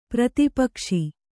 ♪ prati pakṣi